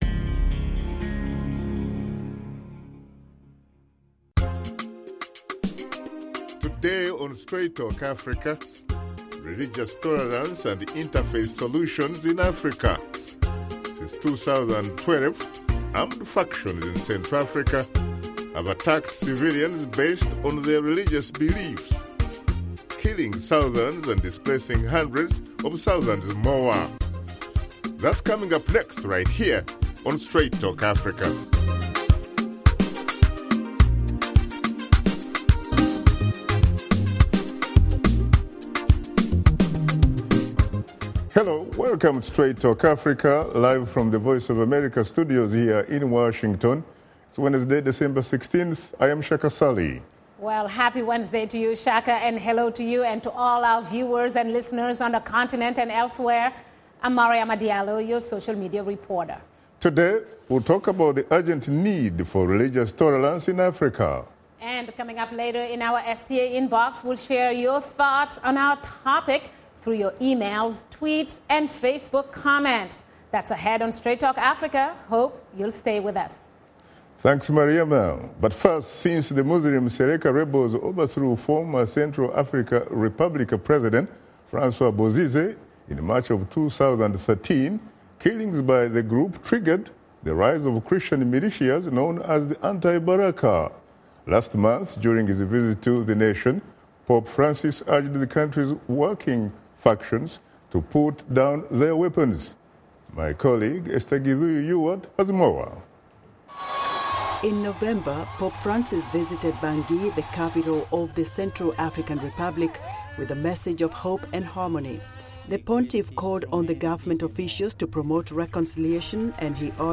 Host Shaka Ssali and his guests discuss religious tolerance in Africa and how interfaith communities are working hard to combat extremism on the continent.